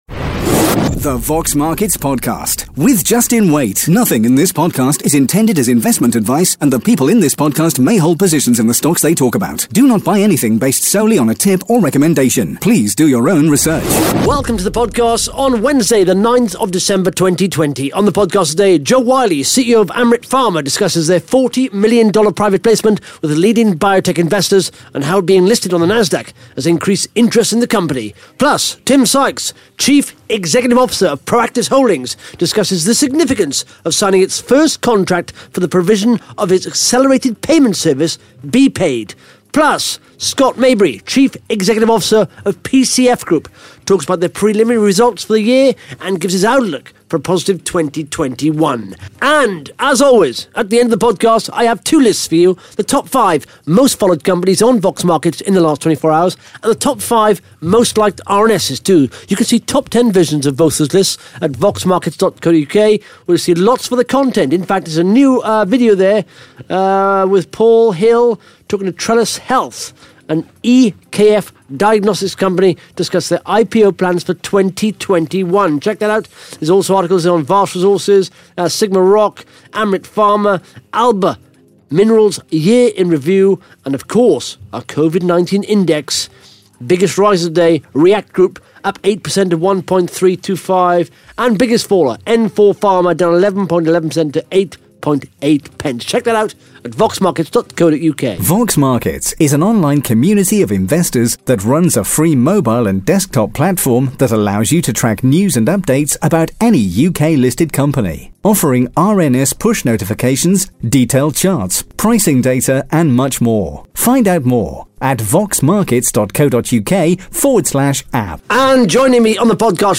(Interview starts at 11 minutes 9 seconds)